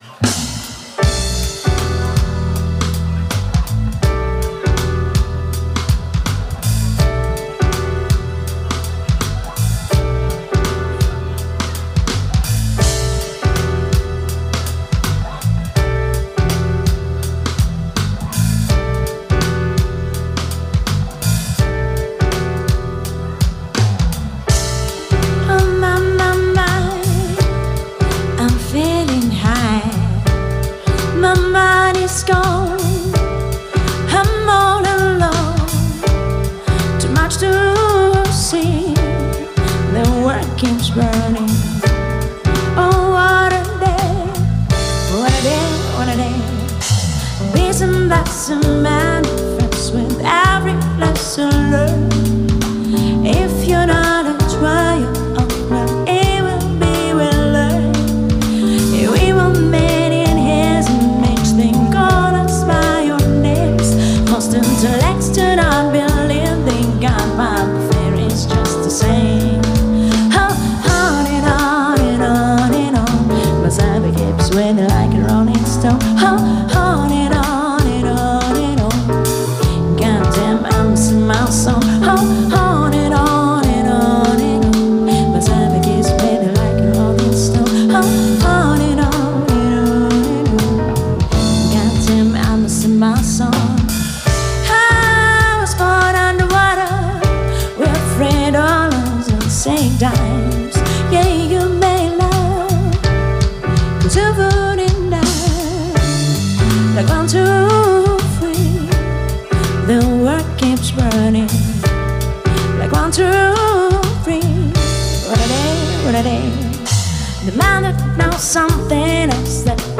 барабаны, бас, клавиши, мужской и женский вокал